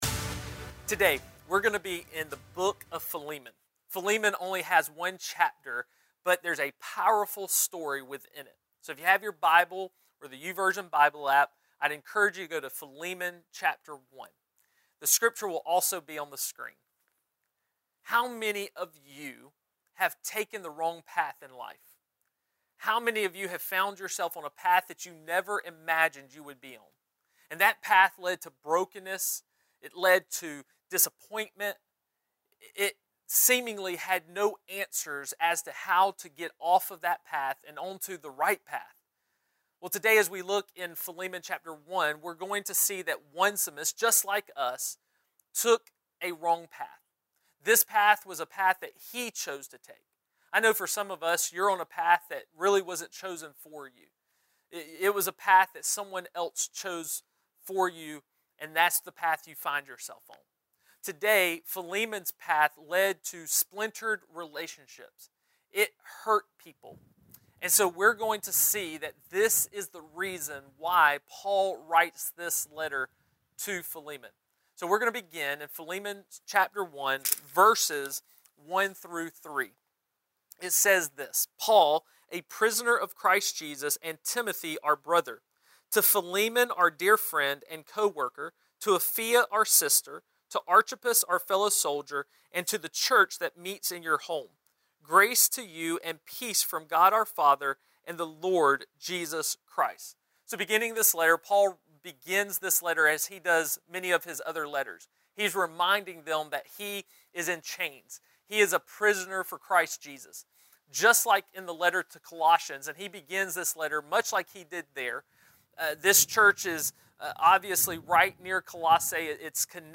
A stand alone sermoin on getting back on the right path with Jesus.